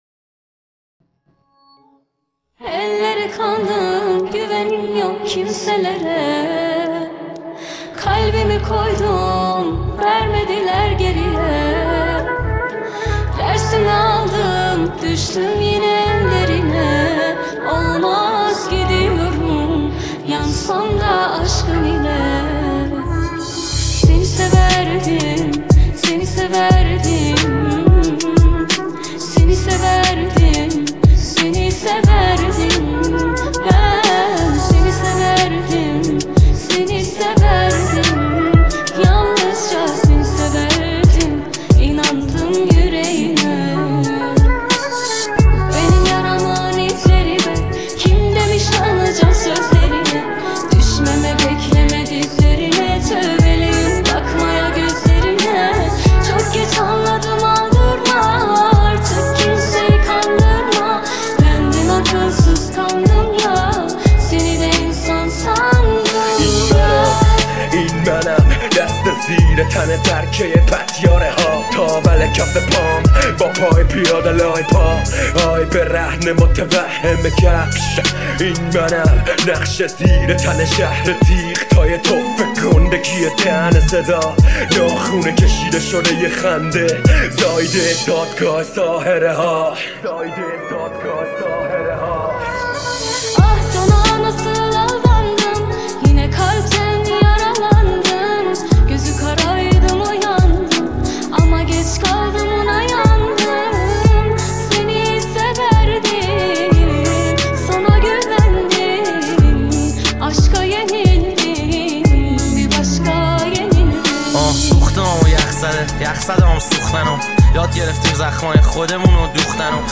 ریمیکس تریبال
ریمیکس خفن رپی